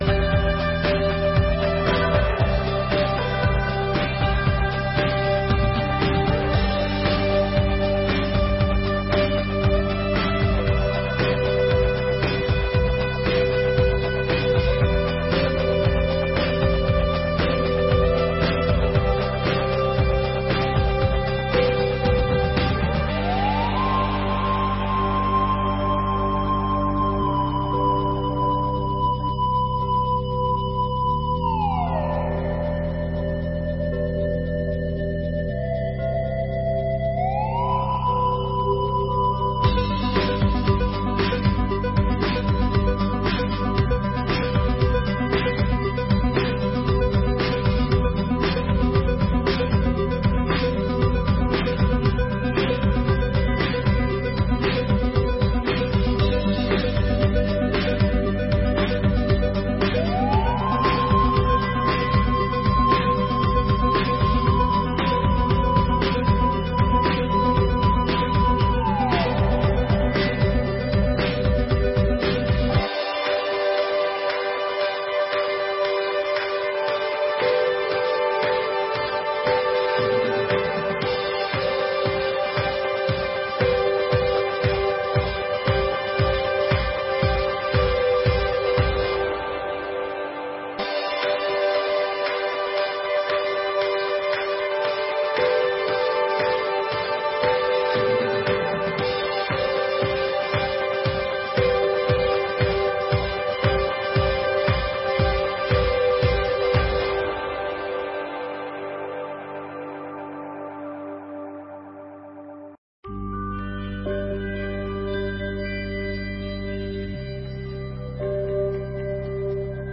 Audiências Públicas de 2022